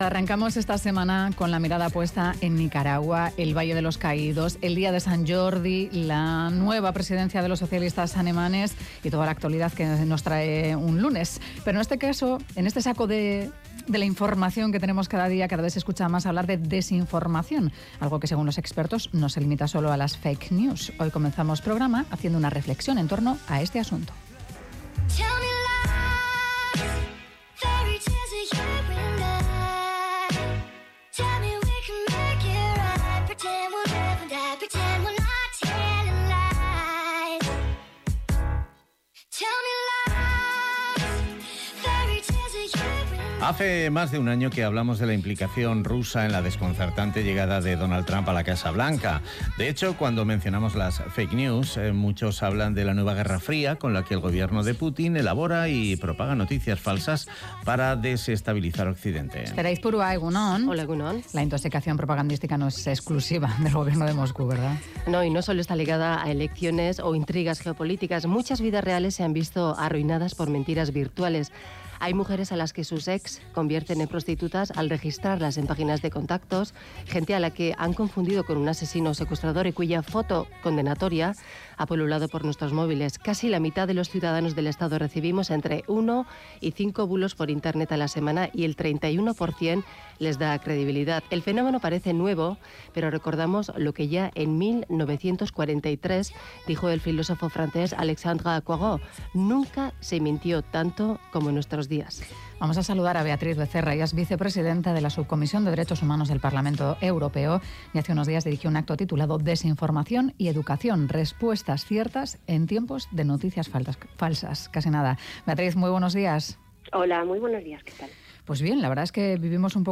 Audio: Beatriz Becerra: Eurodiputada, nos habla de desinformación y educación.